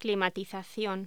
Locución: Climatización
voz